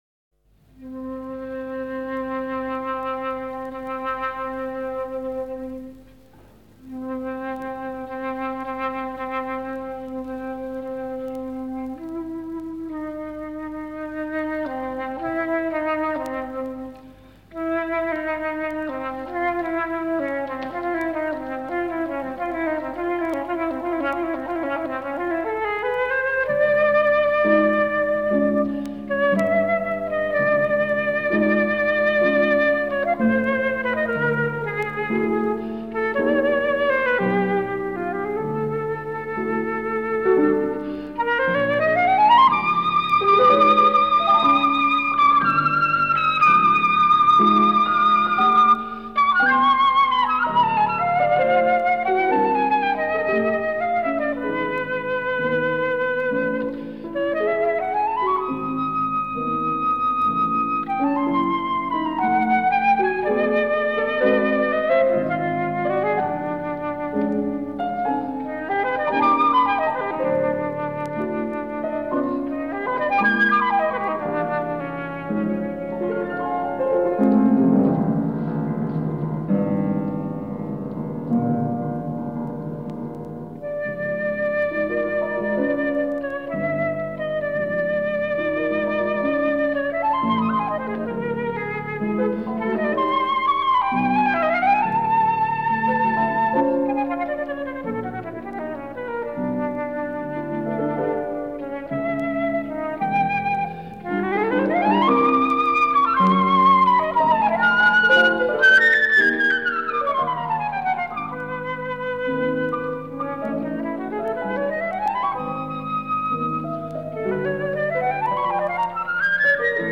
Aurèle Nicolet, flute. Robert Oboussier: Pavane and Galliard for Flute and Piano.
piano.